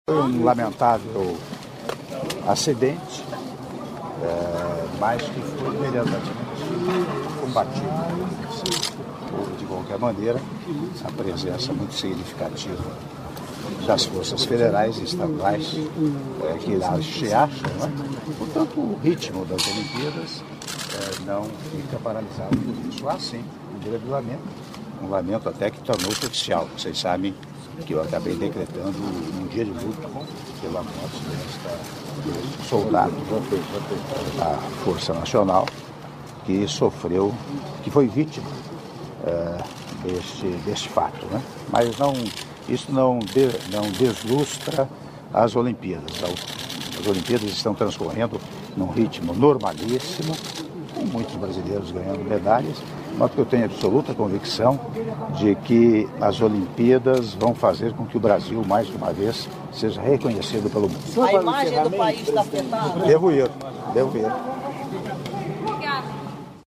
Áudio entrevista concedida pelo Senhor Presidente da República em exercício, Michel Temer, após cerimônia de assinatura de atos internacionais entre o governo do Brasil e o governo da República da Armênia - Brasília/DF - (01min06s)